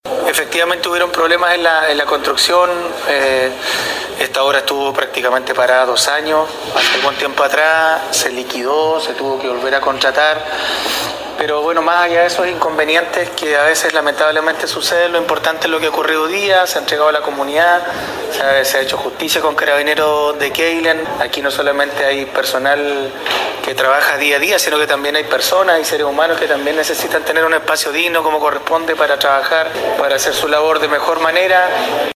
Este jueves se llevó a efecto la ceremonia de inauguración del nuevo edificio que alberga desde ahora al Retén de Carabineros de Queilen, iniciativa que permite contar a la dotación policial de modernas instalaciones para el desarrollo de su labor de resguardo de la comunidad.
En la ocasión, el alcalde de la comuna Marcos Vargas se refirió a la concreción del remozado edificio institucional de un logro largamente esperado por la comunidad, debido a que se trata de un proyecto que sufrió diversos contratiempos.
07-ALCALDE-QUEILEN.mp3